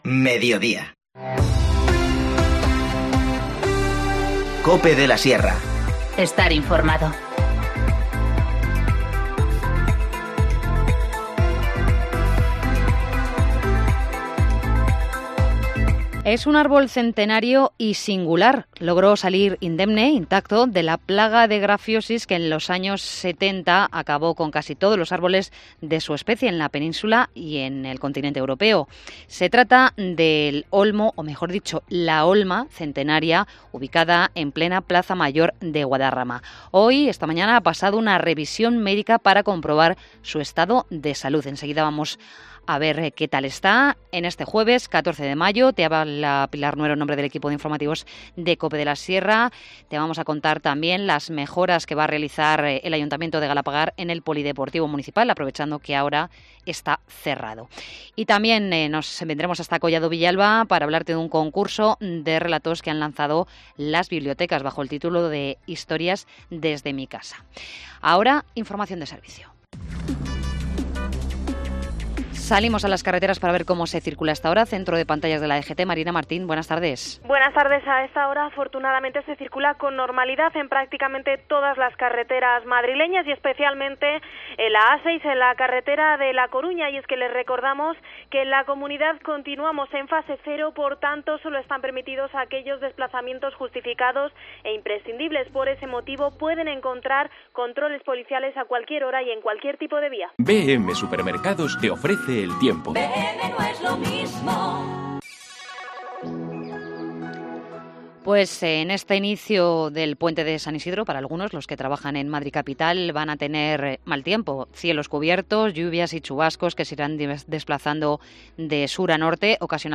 Informativo Mediodía 14 mayo 14:20h